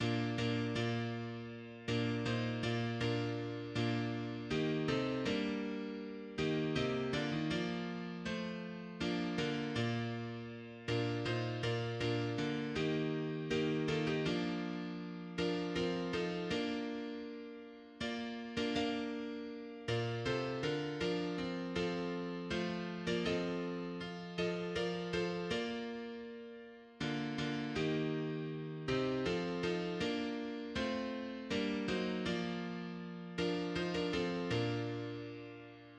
"Calon Lân" (Welsh for 'A Pure Heart') is a Welsh hymn, the words of which were written in the 1890s by Daniel James (Gwyrosydd) and sung to a tune by John Hughes.[1] The song was originally written as a hymn,[2] but has become firmly established as a rugby anthem, associated with the Welsh rugby union, being sung before almost every Test match involving the Welsh national team – though more likely to be heard sung at matches involving the Welsh football team in recent years.
The opening few bars of Calon Lân, sung by Stuart Burrows